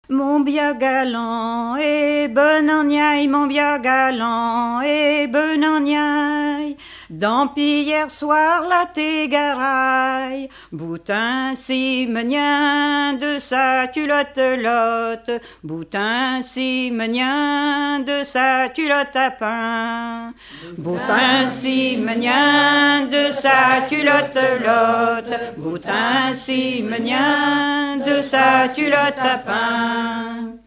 Genre laisse
Enquête Arexcpo en Vendée-Pays Sud-Vendée
Pièce musicale inédite